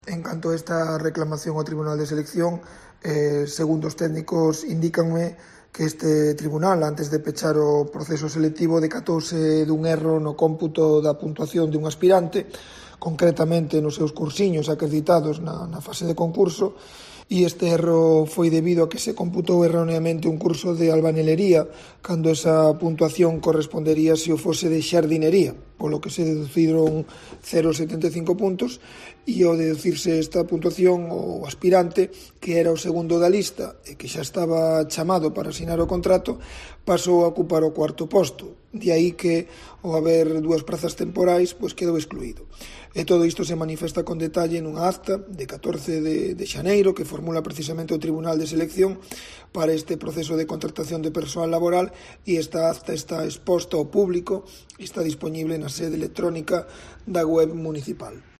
Declaraciones de FERNANDO SUÁREZ